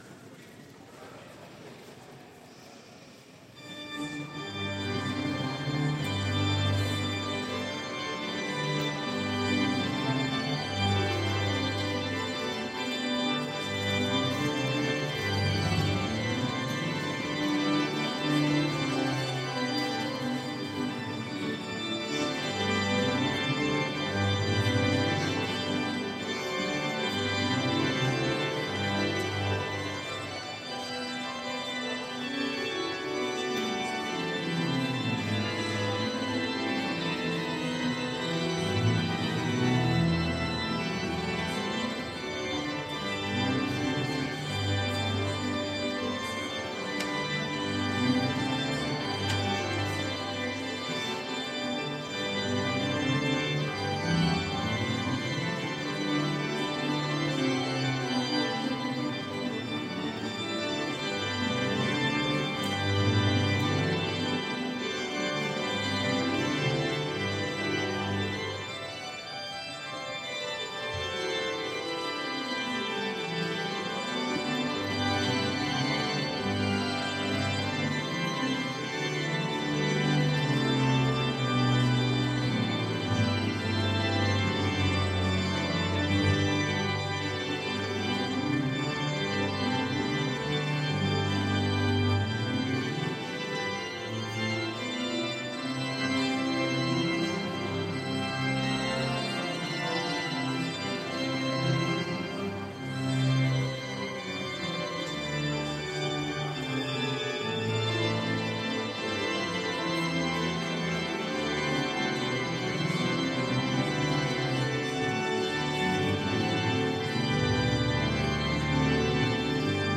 Culte à l’Oratoire du Louvre